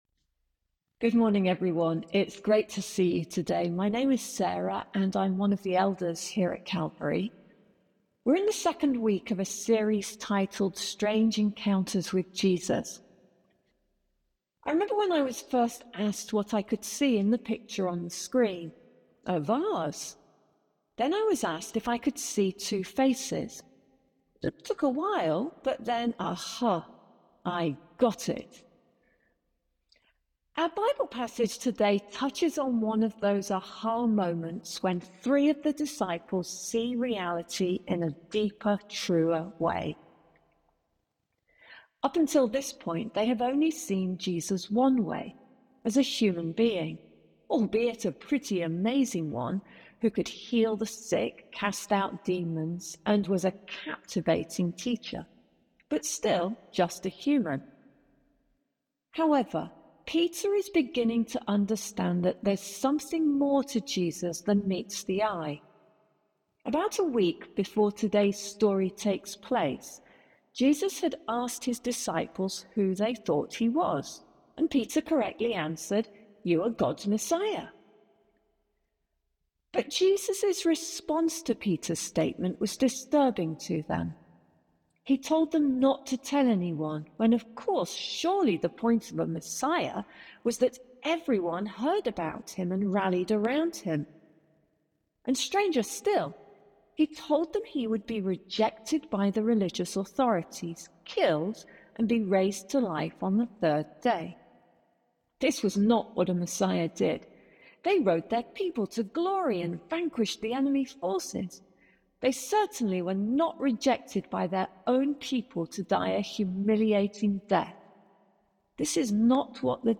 Sermons | Calvary Baptist Church
Due to technical issues during the Sunday service, our live-stream was interrupted. The audio recording was salvaged and partially re-recorded.